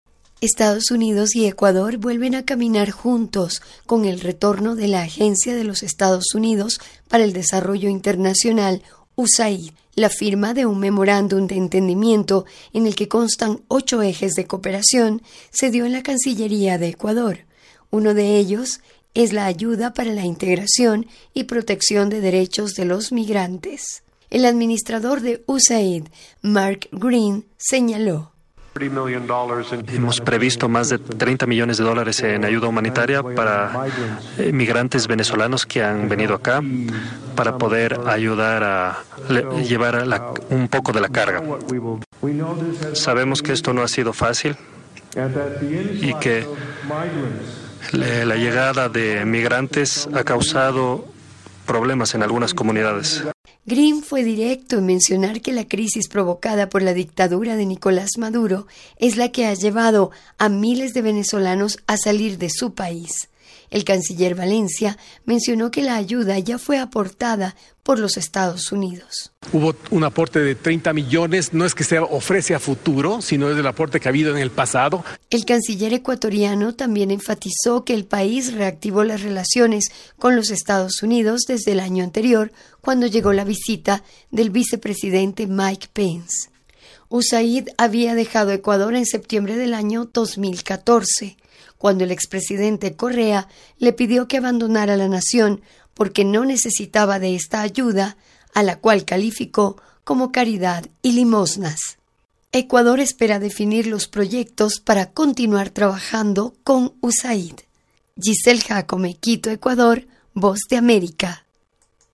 VOA: Informe desde Ecuador